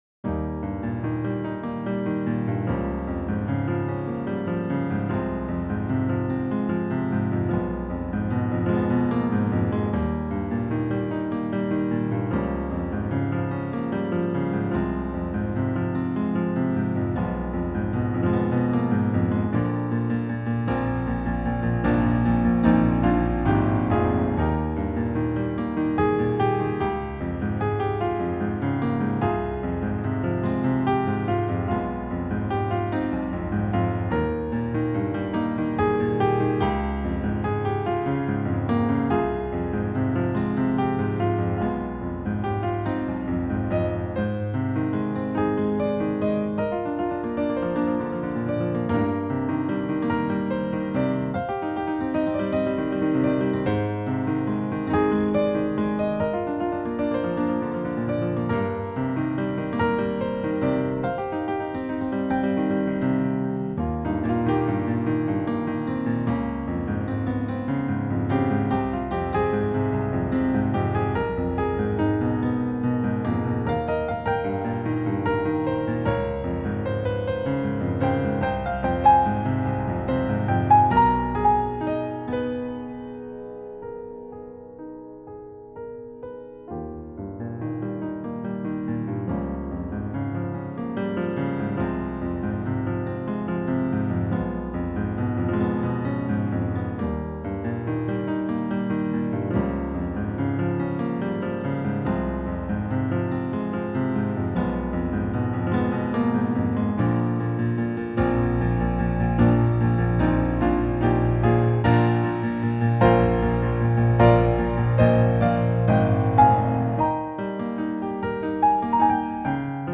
on Piano.